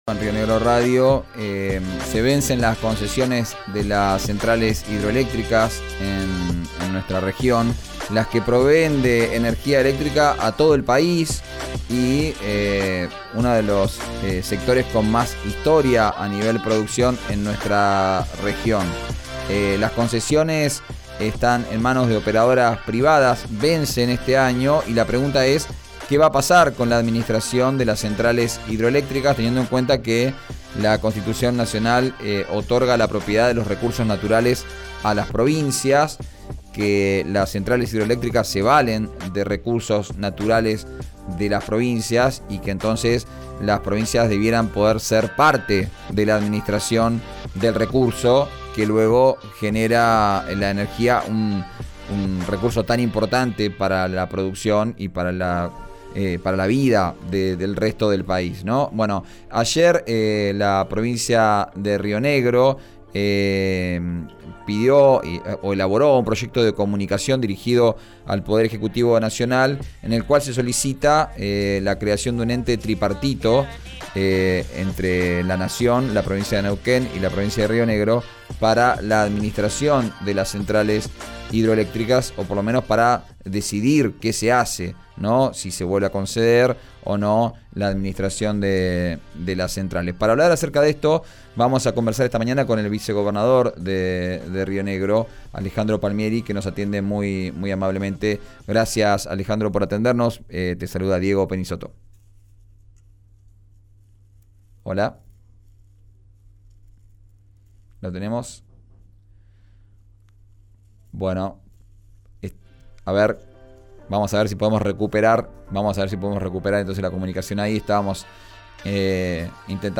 En diálogo con «Vos al aire», por RÍO NEGRO RADIO, el vicegobernador de Río Negro Alejandro Palmieri explicó los fundamentos de la iniciativa.